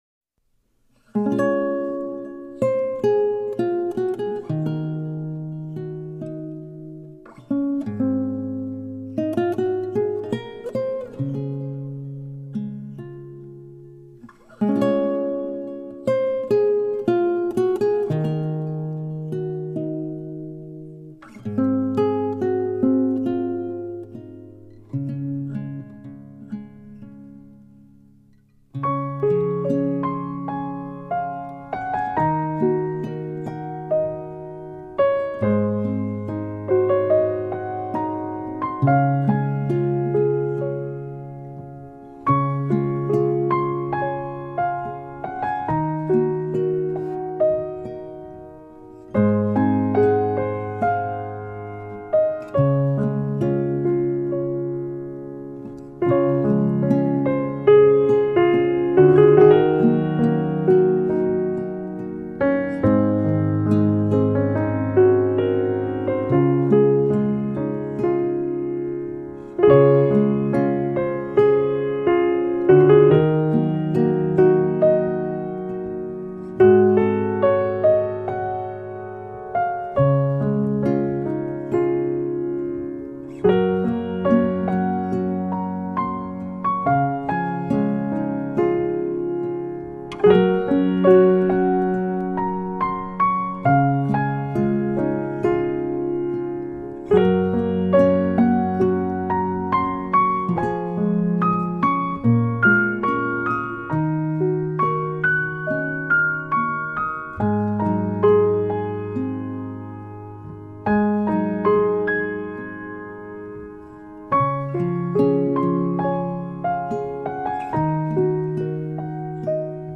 淡淡哀愁的钢琴音色，铺陈一段段邂逅、爱与离别的故事，
部分曲子加入弦乐、吉他、手风琴等乐器，呈现更丰富的音乐氛围。
用最温柔、平和的曲调表现出来。